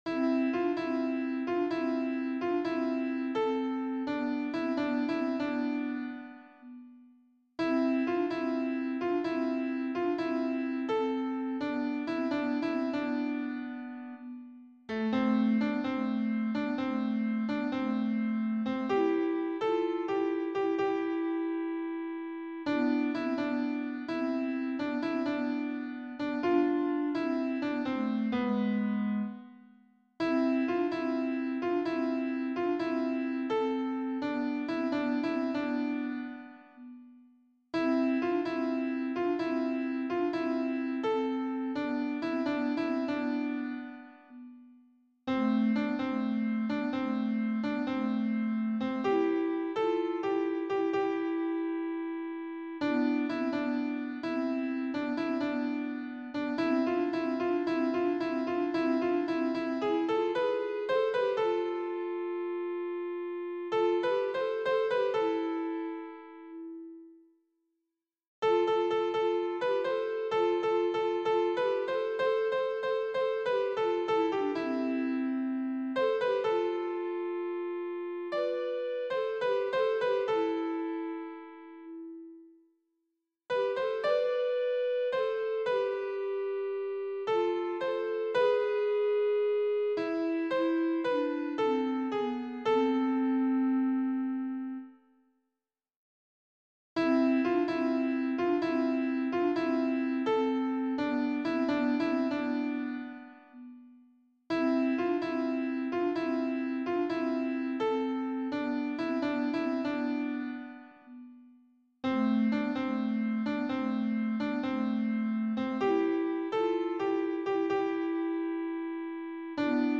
sopranes alti et hommes